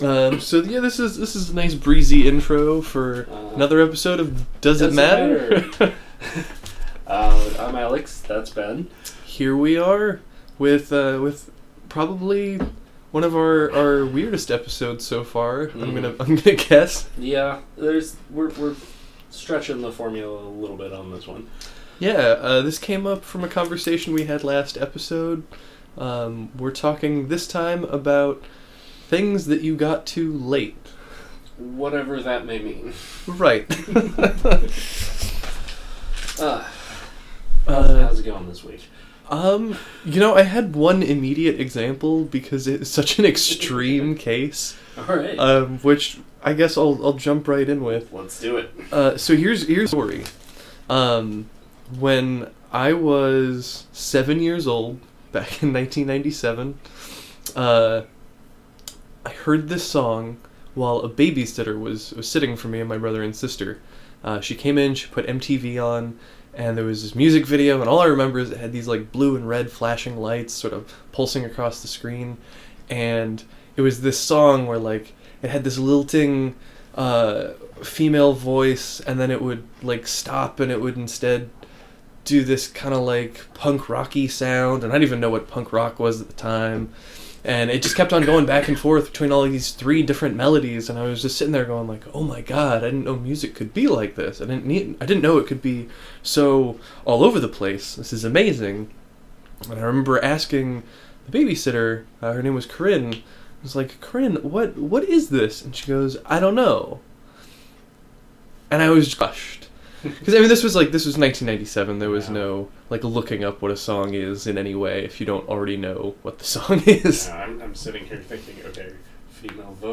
A discussion of works we were too late to experience to be able to enjoy.